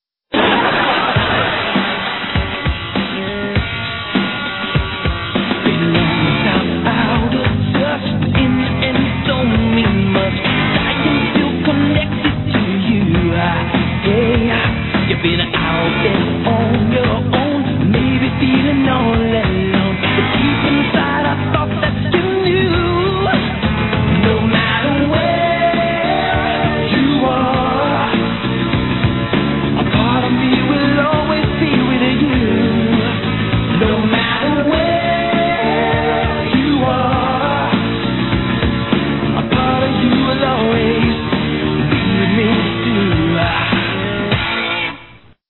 Tags: TV Songs actors theme song show